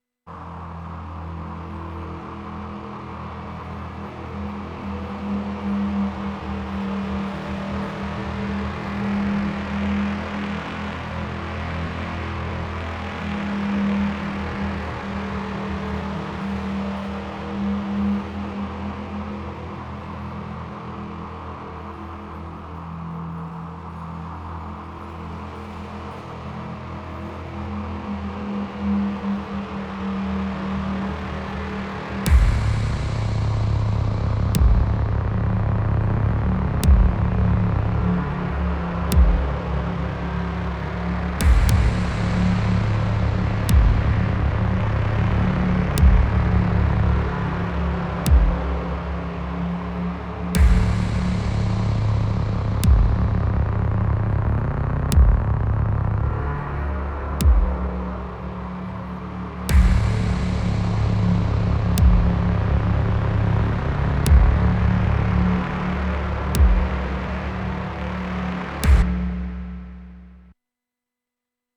I made this small dark ambient beat jam thing as a test to see if this device was what I was looking for (stereo sampling + all the other goodness) and yes I couldn’t do this on the Digitakt so I am happy.
Learning to set up record trigs, thru tracks, sampling my Syntakt in and making a loop out of a synth was definitely one of the highlights of yestreday too, so much possibilities that I couldn’t do with only a digitakt.